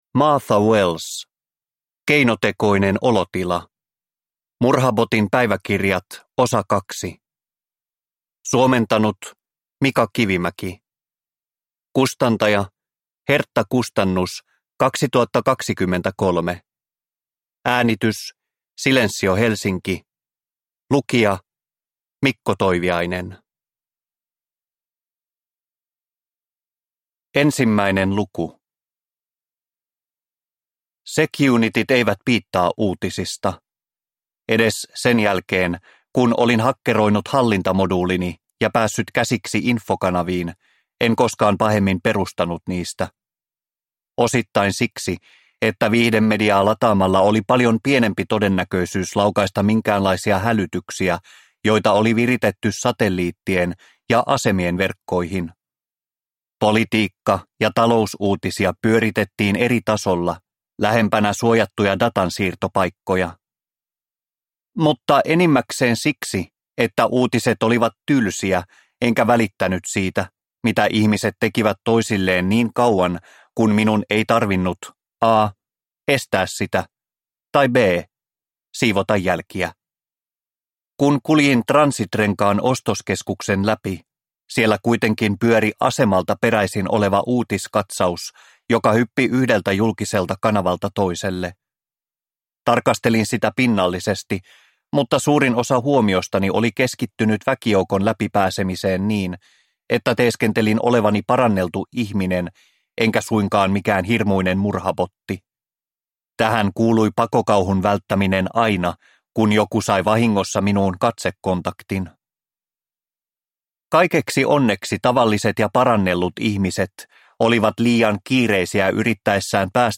Keinotekoinen olotila – Ljudbok – Laddas ner